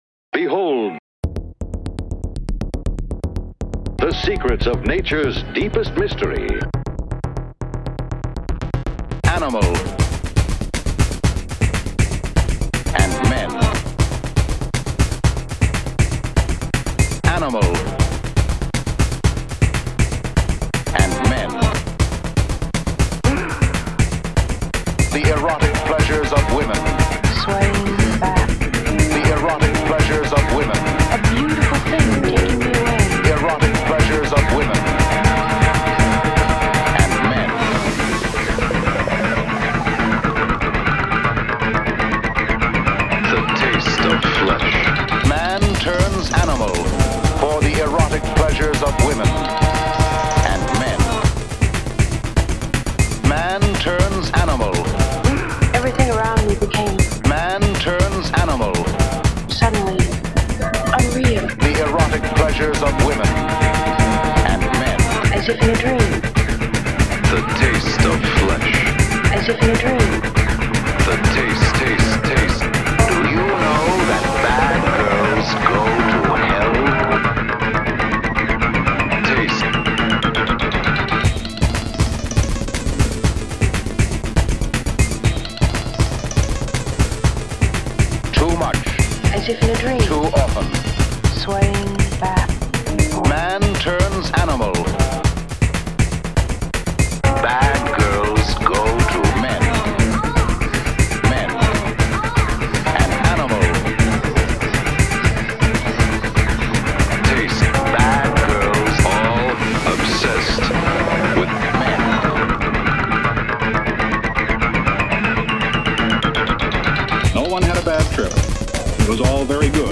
Genres: Rock / Alternative / Indy
(no lyrics)